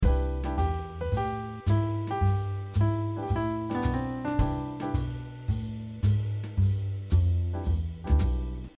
3. Play over the bar line by anticipating notes
Take a look at how this following example uses the anticipated notes on the ‘and’ of beat 4.